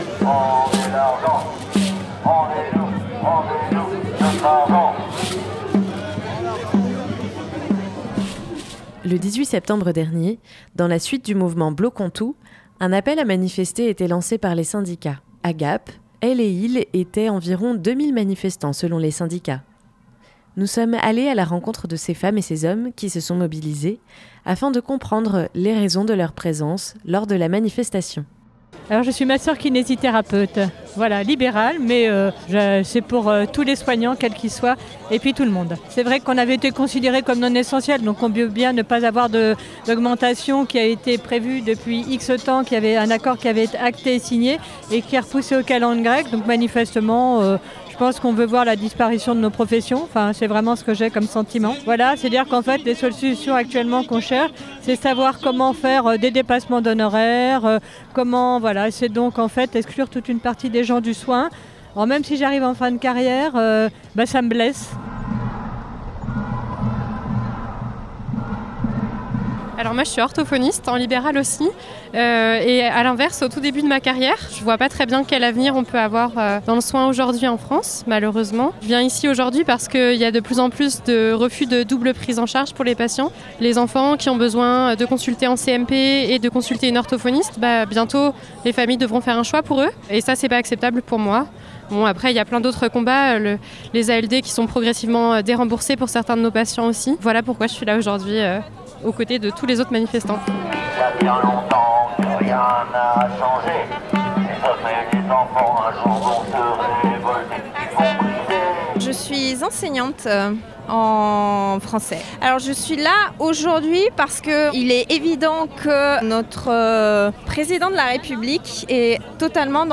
Nous sommes allés à la rencontre des manifestantes et manifestants pour écouter les raisons de leurs mobilisations.